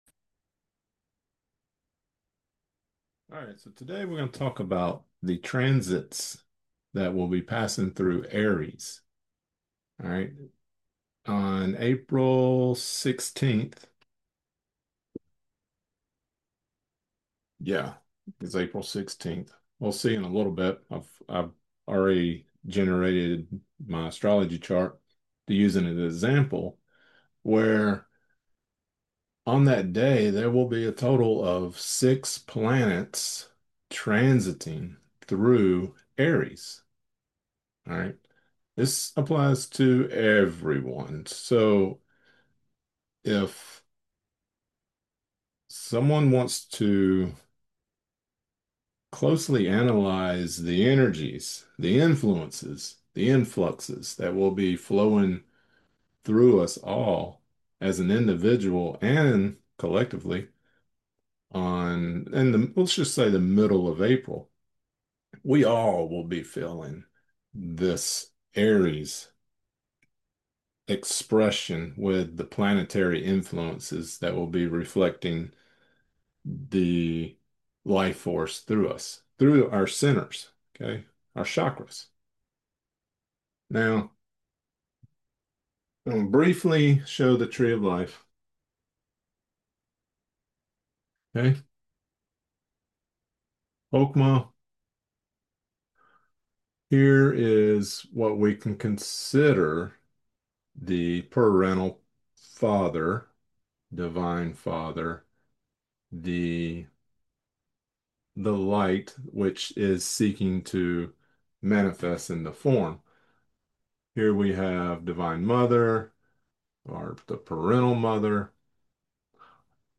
With Aries creating strong impactful opportunities this month for excitement we hopefully will consciously acknowledge, let us take some moments and try to understand the planetary relationships that are happening this month and our consciousness growth. Lecture Created Transcript Blockchain Lecture slides 04/06/2026 Lecture audio only 04/06/2026 Watch lecture: View 2026 Lectures View All Lectures